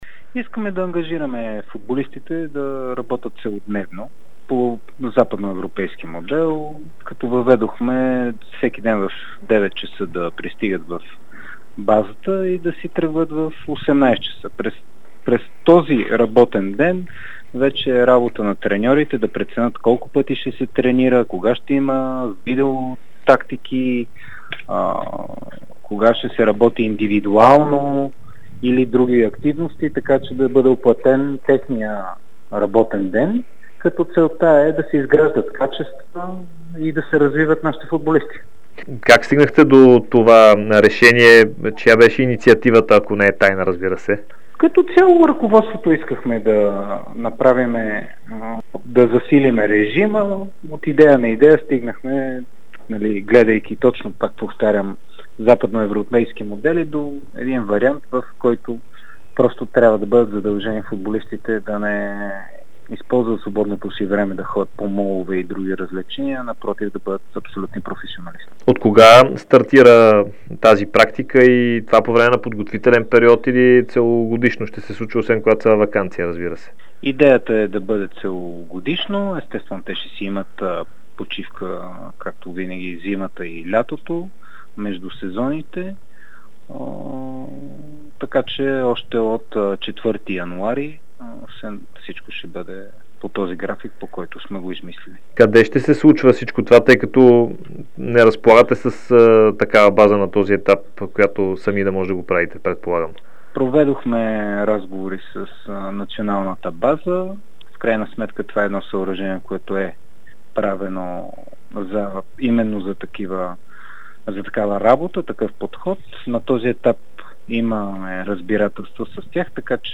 специално интервю за Дарик радио и dsport